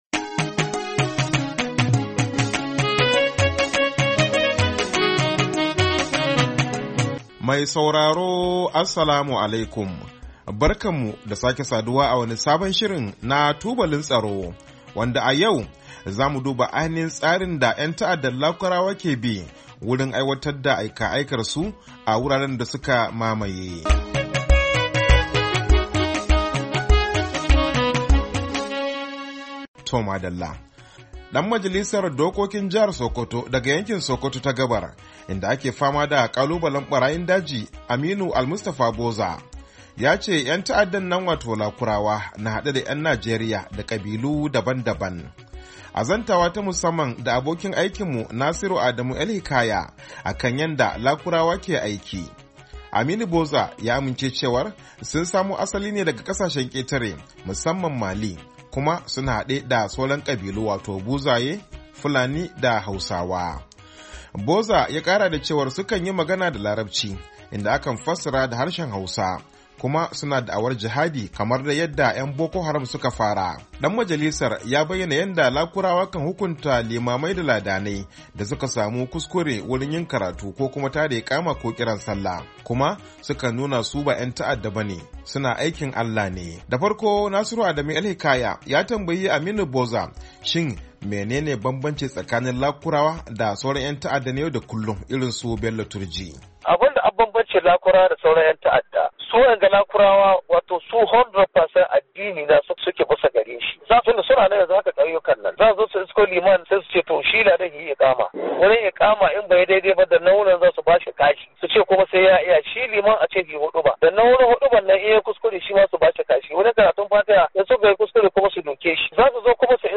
TUBALIN TSARO: Hira Da ‘Dan Majalisa Akan Barayin Daji, ‘Yan Ta’adda A Jihar Sakkwato, Nuwamba 29, 2024.mp3